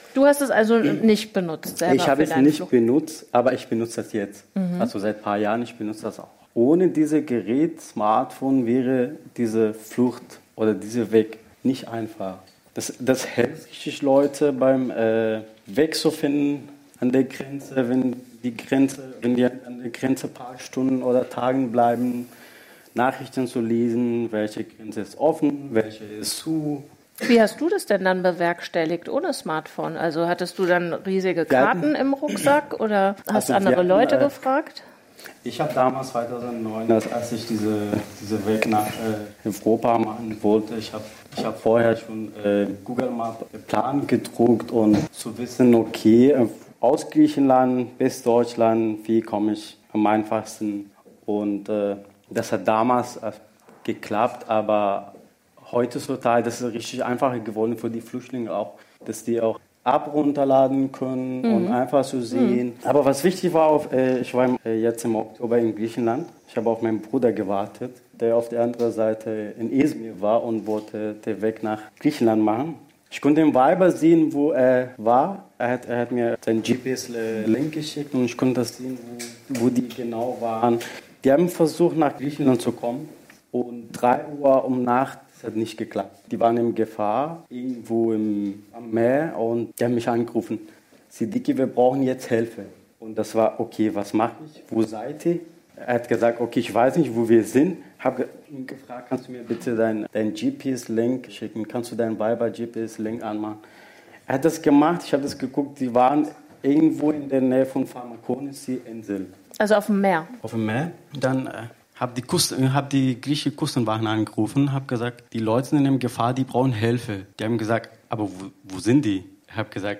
Viele Flüchtlinge nutzen auf der Flucht ein Smartphone, um so Kontakt zu Schleppern aufzunehmen, als Navigation und Medium, um sich in der neuen Heimat zu vernetzen. Dazu gab es bereits im Oktober 2015 eine sehr interessante Veranstaltung, die aufgezeichnet und auf Deutschland Radio Wissen / Hörsaal als Podcast zur Verfügung steht.